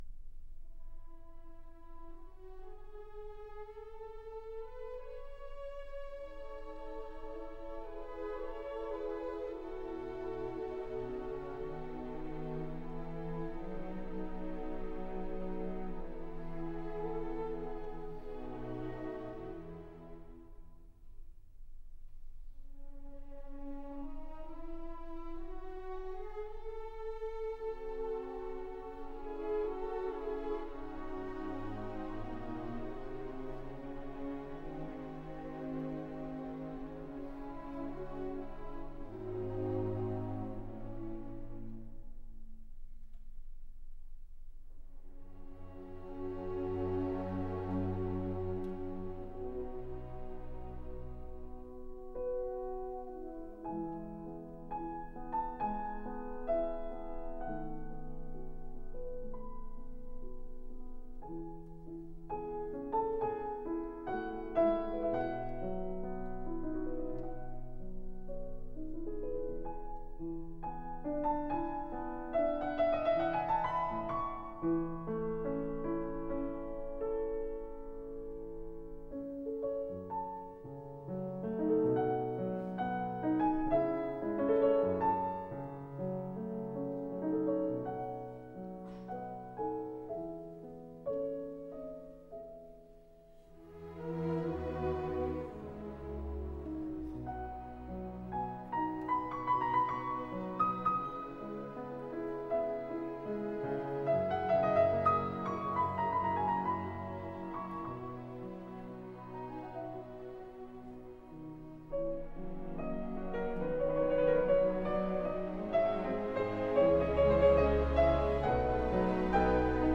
02.Piano Concerto No.1 in e-moll
Romance. Larghetto